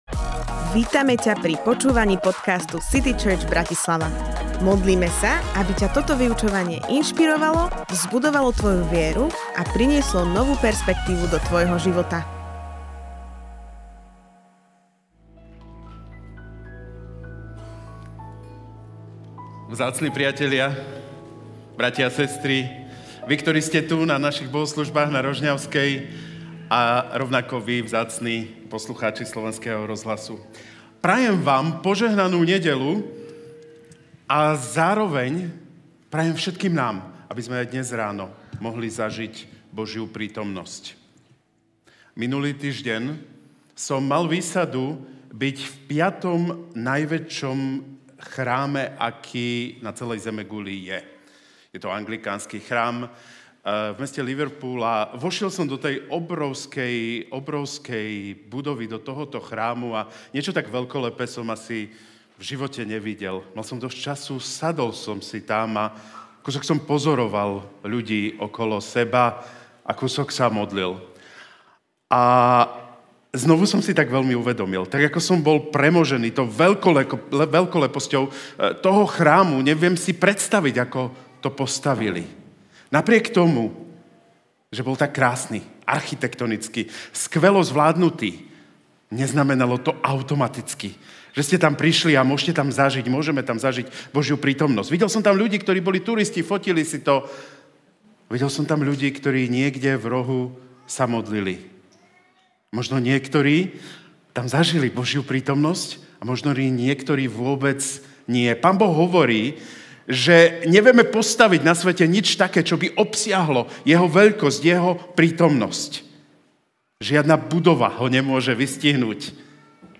Premieňajúca prítomnosť Kázeň týždňa Zo série kázní